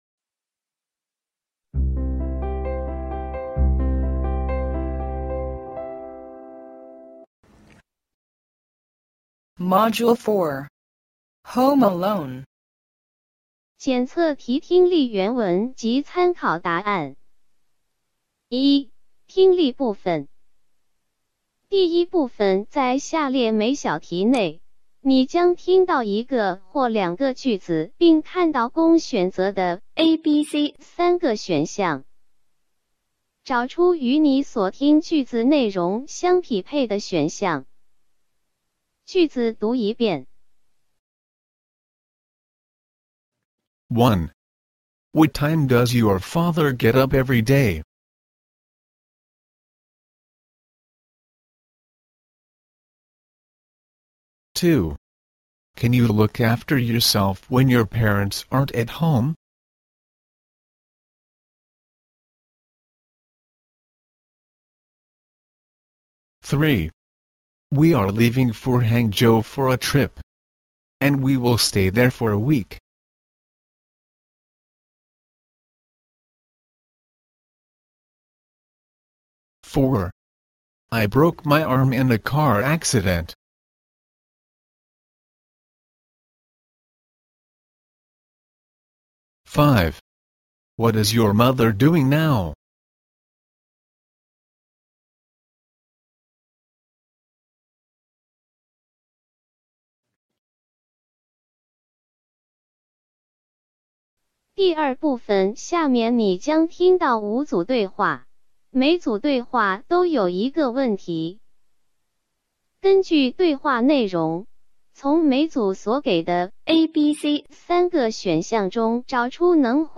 配套《中学教材全解》九年级英语（上）（外研版）MODULE 4检测题听力录音.mp3